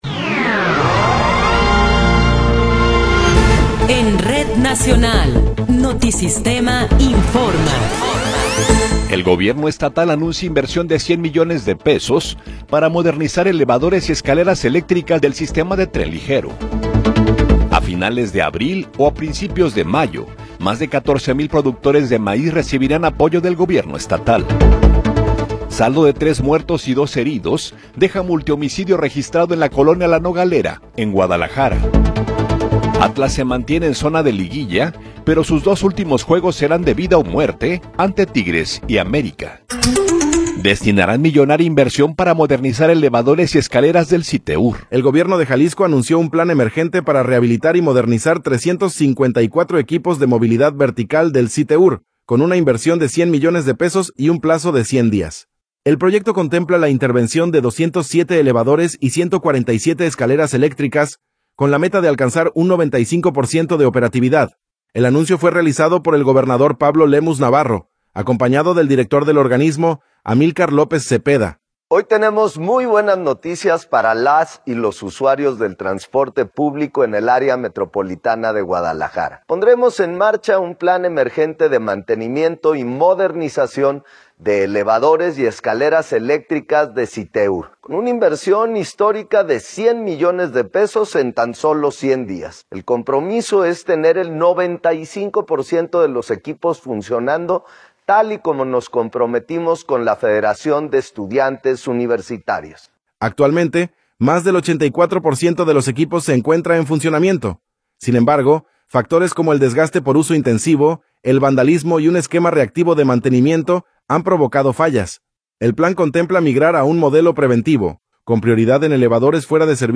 Noticiero 9 hrs. – 20 de Abril de 2026
Resumen informativo Notisistema, la mejor y más completa información cada hora en la hora.